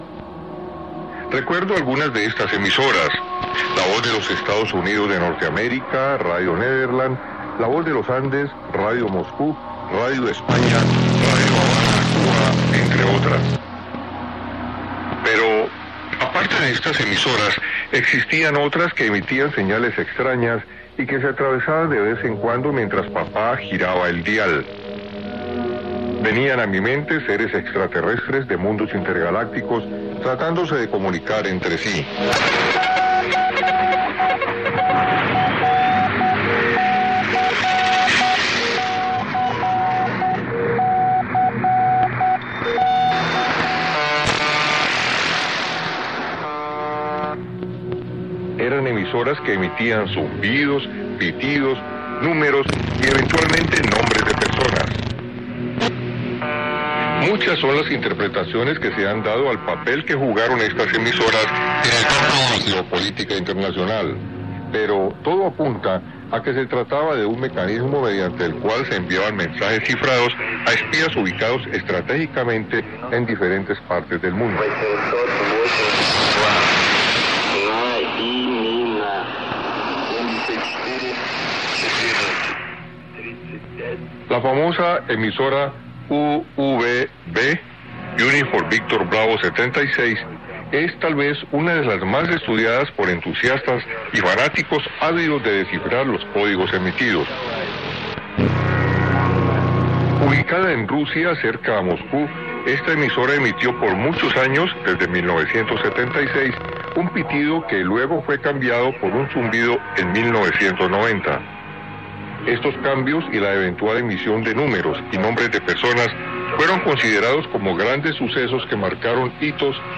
Ante la ausencia del servicio eléctrico en mi zona existía nulo ruido eléctrico.
Sintonizando encontré la señal del programa “Frecuencia al día” que se transmite por los 17,775 khz de onda corta.
Este es un extracto del programa sobre la onda corta… captada en la onda corta, así que en determinado momento no sabemos si los desvanecimientos, pitidos y zumbidos corresponde al programa o a la señal que capto en mi pequeño aparato receptor.
Equipo receptor y grabador: TIVDIO V-115
Antena: Longwire de 10 metros de longitud con toma a tierra.